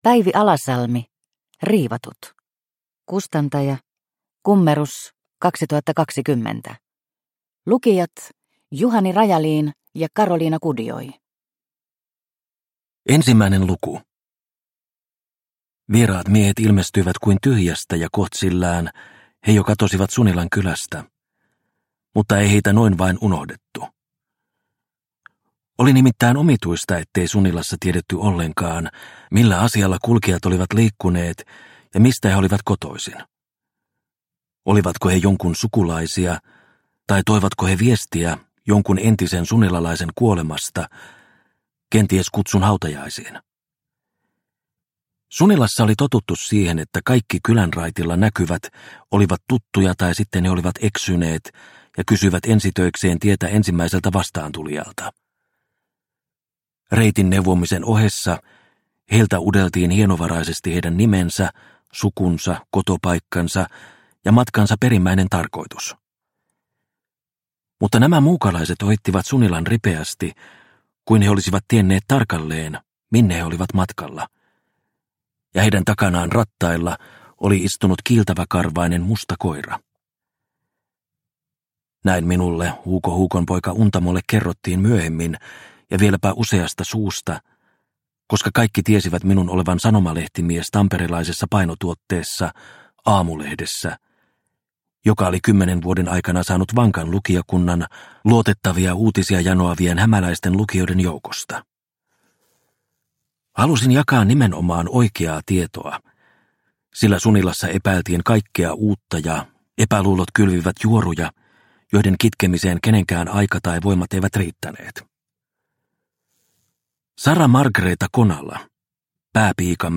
Riivatut – Ljudbok – Laddas ner